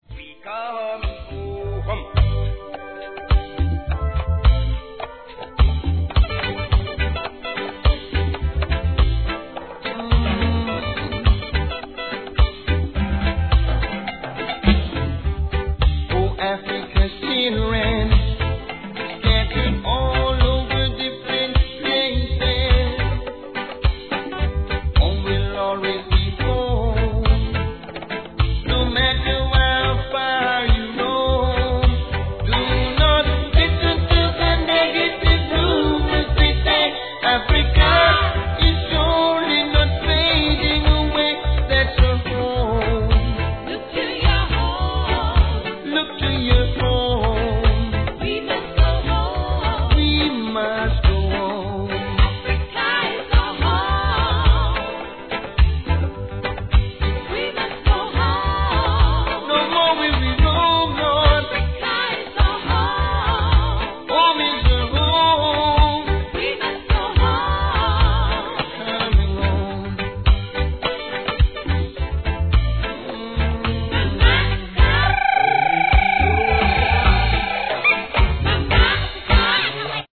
REGGAE
対エチオピア難民チャリティのレゲエ・アーティスト編!!